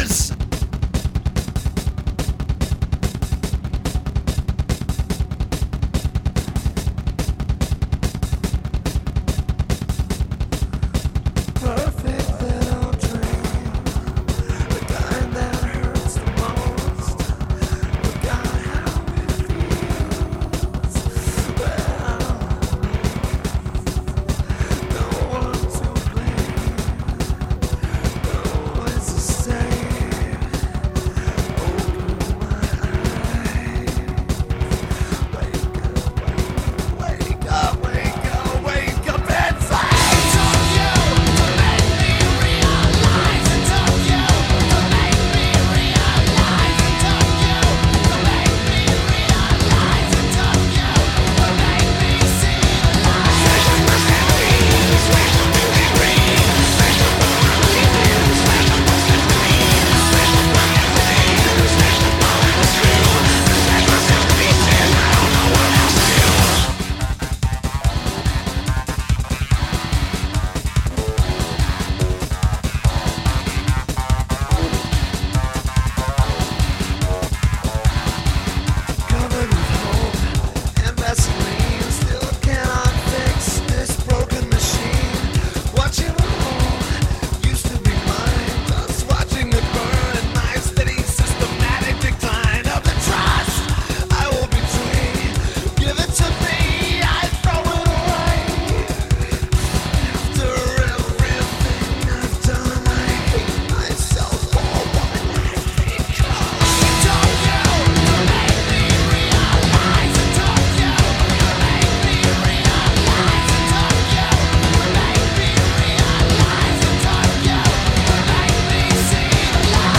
. cut - pieces are cut out to trim it down